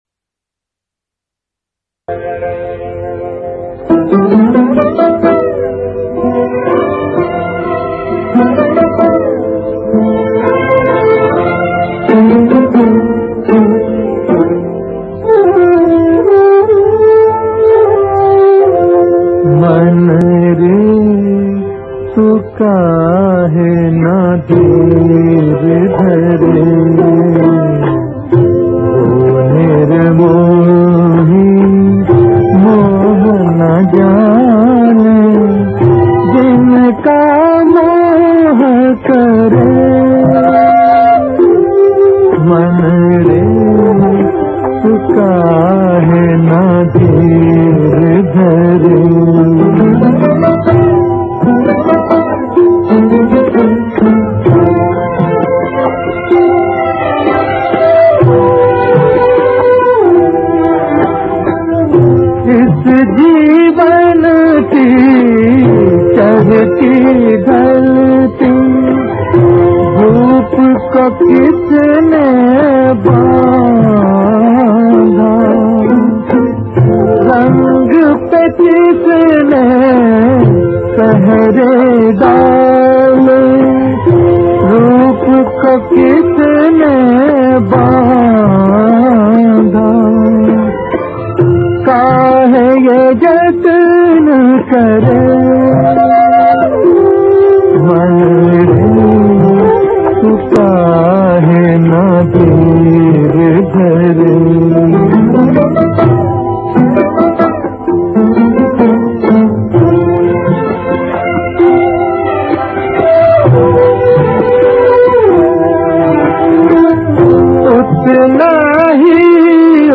And then out of the blue this classic song came on.